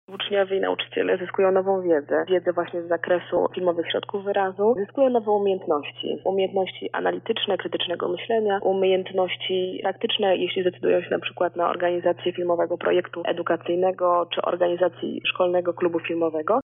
O tym co zyskują uczestnicy akcji mówi jedna z koordynatorek projektu